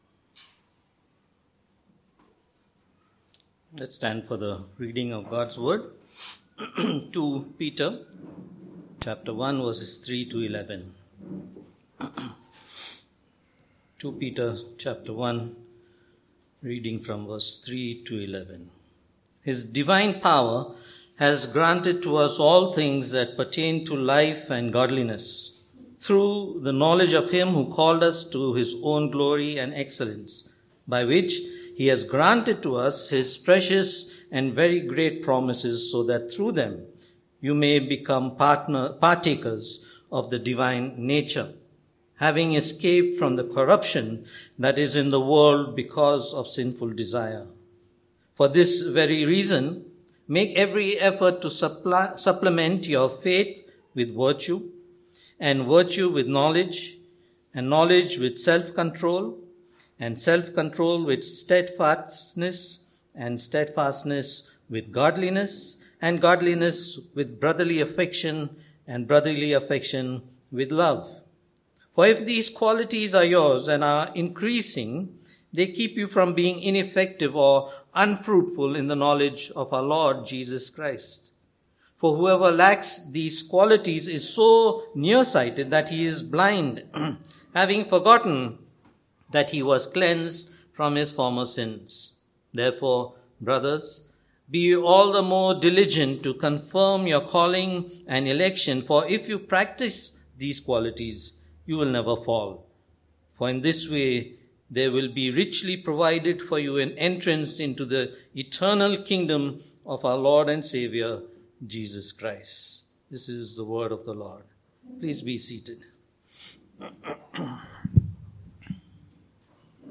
Passage: 2 Peter 1:3-11 Service Type: Sunday Morning